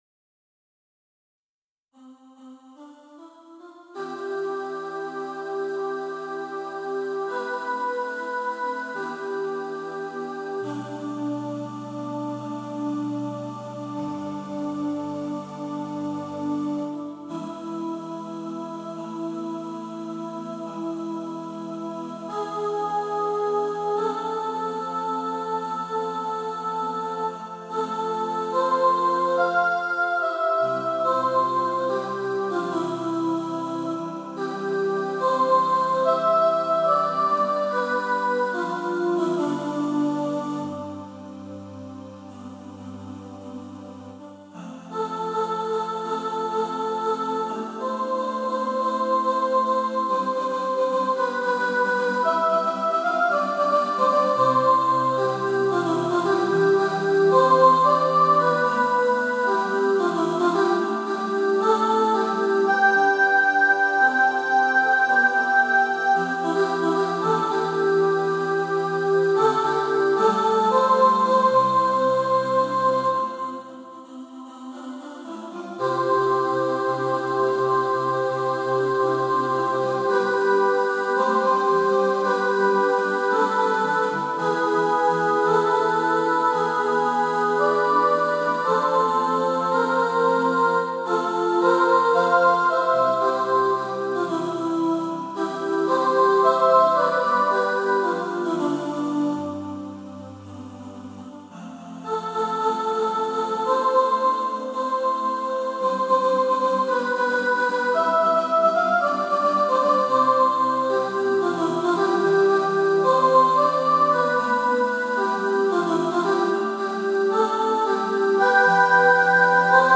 A-Little-Respect-Soprano1.mp3